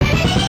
jingles-hit_00.ogg